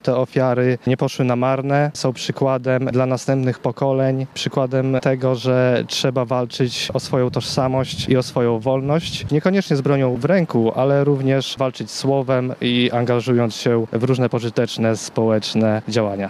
Zamość: obchody 74. rocznicy powstania warszawskiego